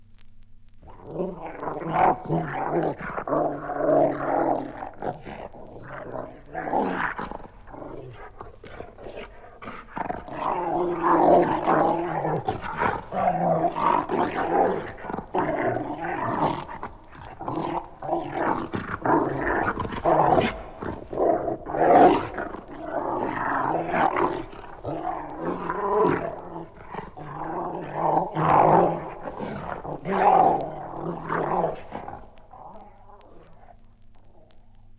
• the hell-hound growling and snarling.wav
the_hell-hound_growling_and_snarling_l4S.wav